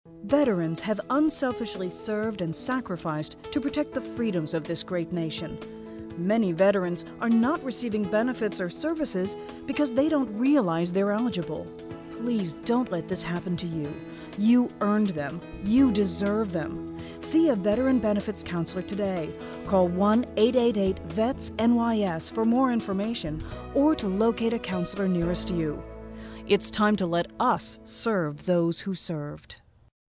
NYS Division of Veterans' Affairs Public Service Announcements
VO_sample_-_NYS_Vets_Affairs_PSA_30sec.mp3